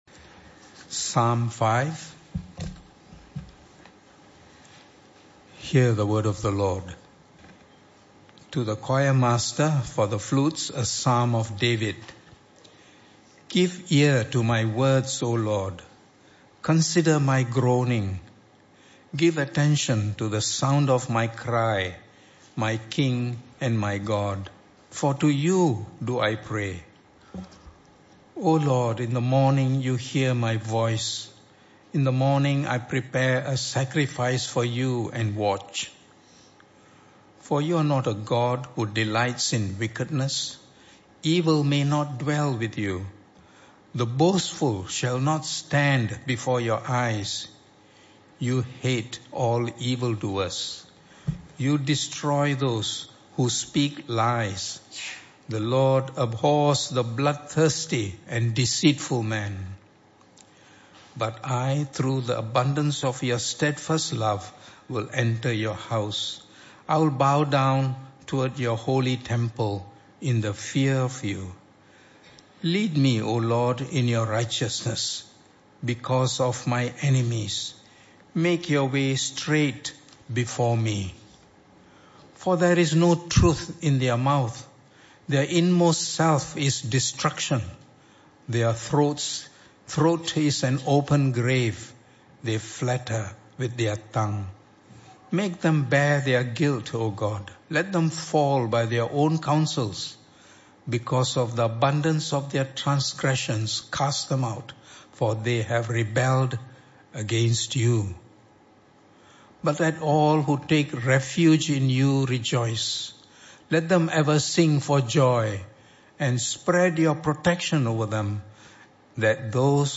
this talk was part of the AM Service series entitled Songs Of The King.